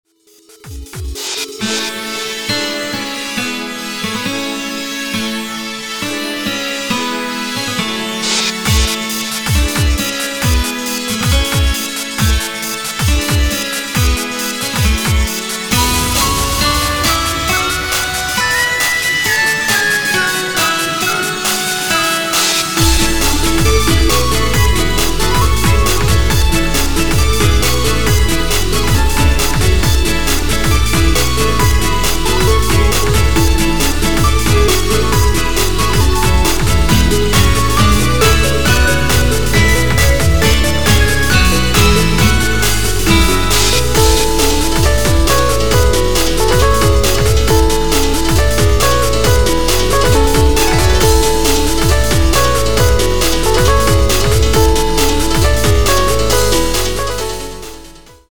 Demo聞いていただければ判るとおり、何時もの俺じゃないです。